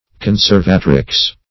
Search Result for " conservatrix" : The Collaborative International Dictionary of English v.0.48: Conservatrix \Con`ser*va"trix\, n. [L.] A woman who preserves from loss, injury, etc. [1913 Webster]